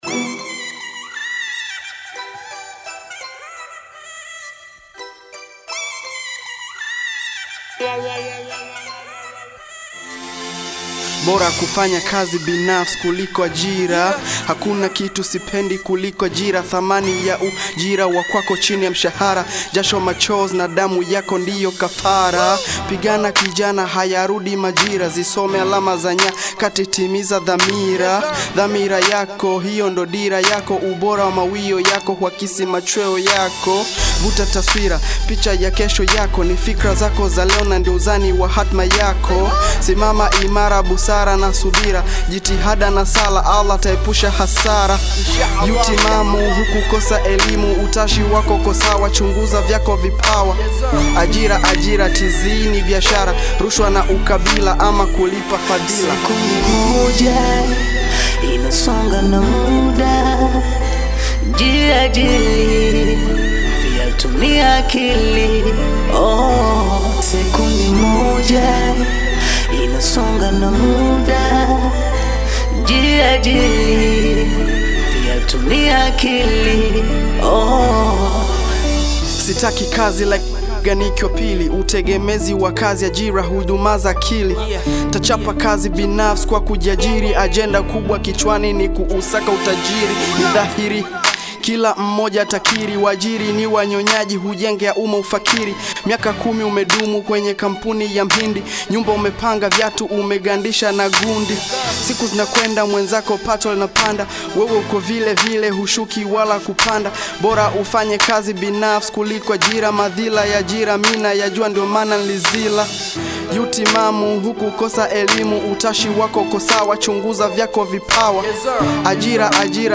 Bongo fleva / Hip hop